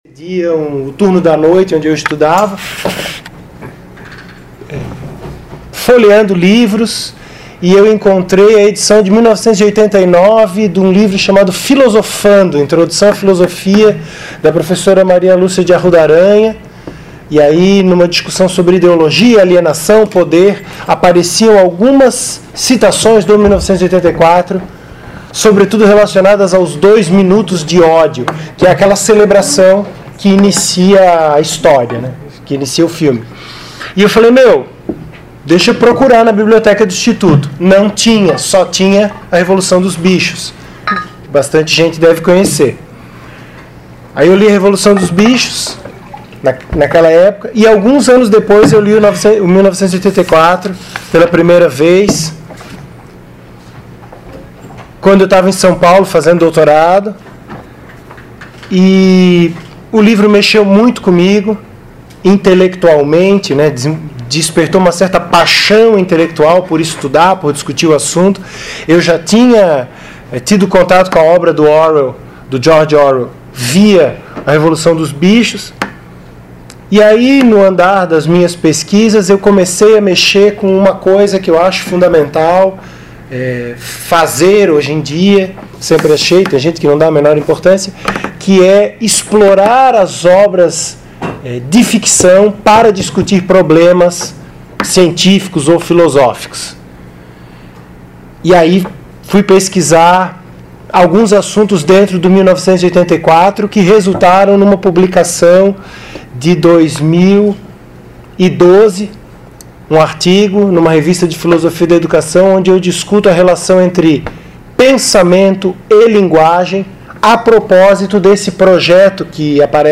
Open Debate Retrato